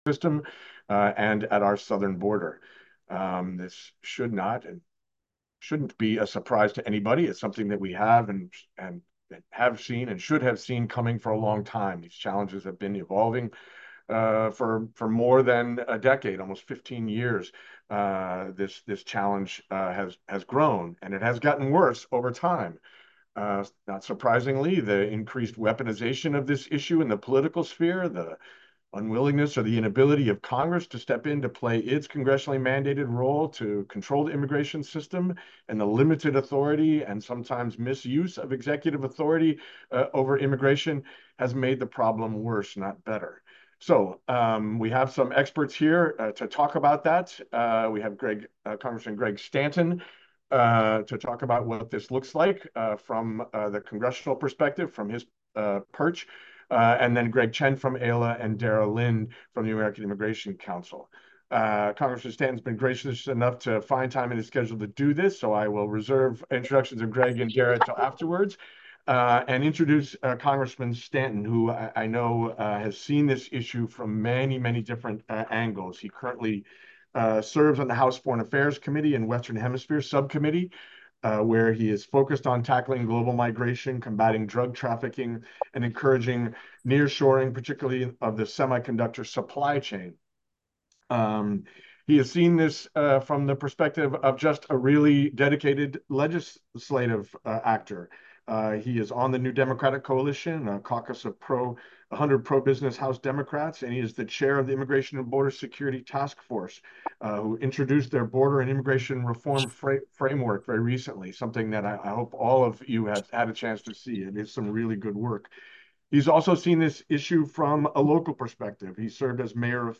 Audio Recording of May 30, 2024, Press Briefing with Rep. Stanton and AILA and Council Border Policy Experts